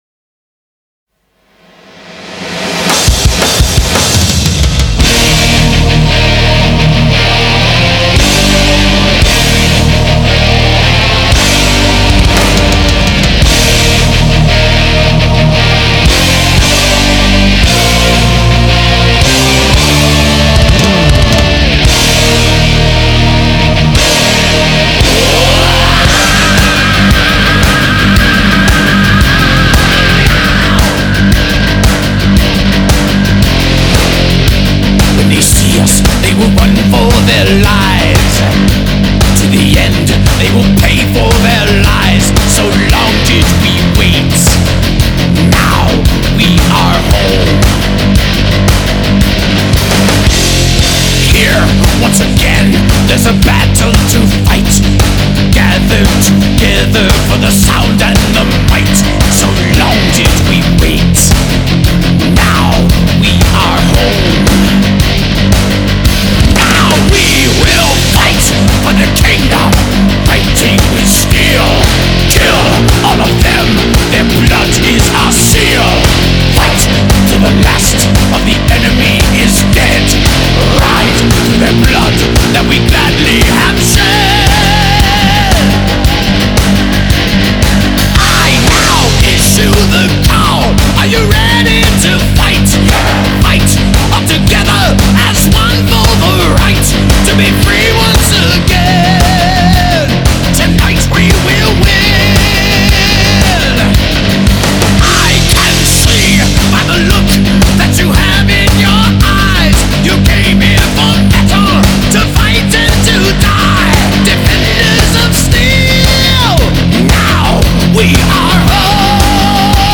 اهنگ حماسی و احساسی
genre:power metal/haevy metal